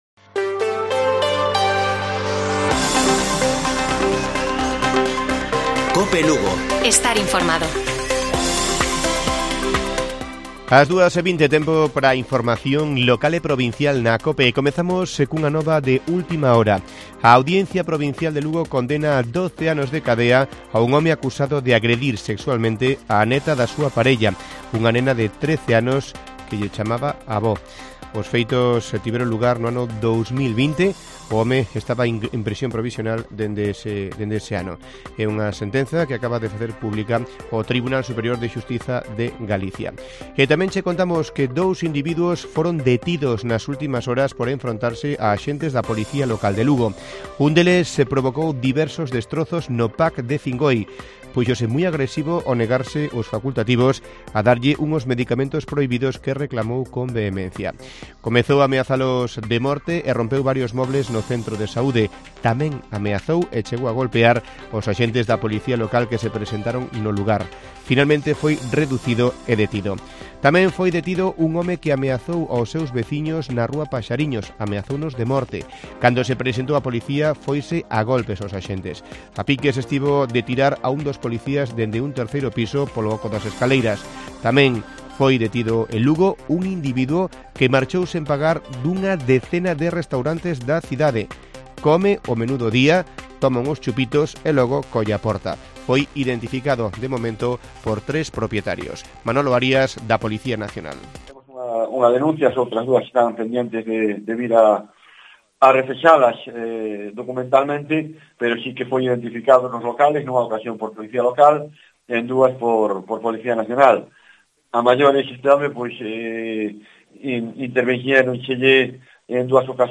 Informativo Mediodía de Cope Lugo. 02 de febrero. 14:20 horas